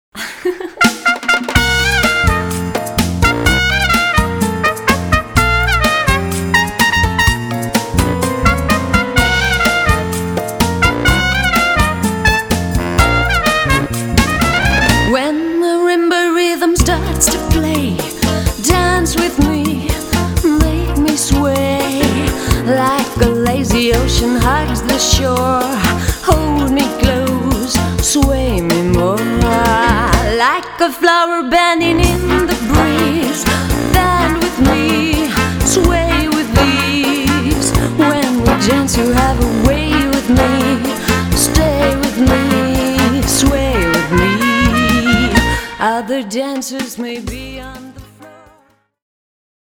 Sängerin, Sänger/Keyboard, Saxophon/Bass, Gitarre, Drums